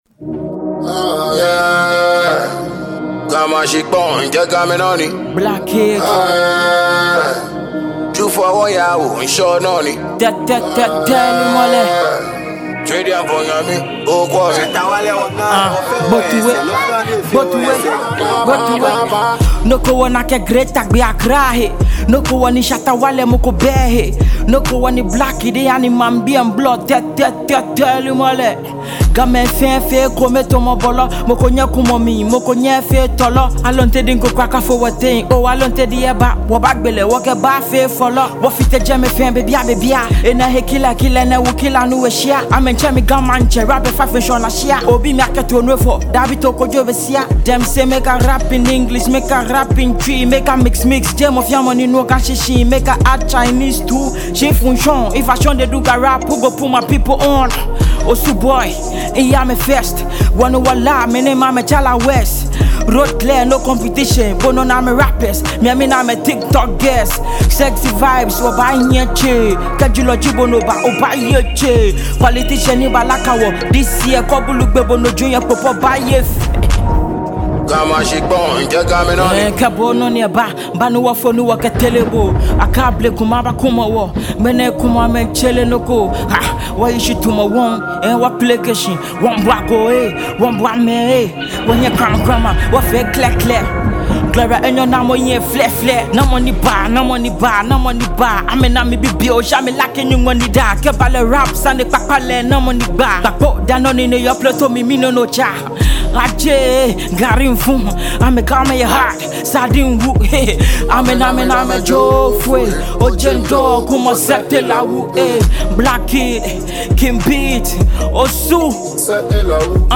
hard-hitting punchlines and a hardcore rap style